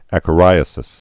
(ăkə-rīə-sĭs)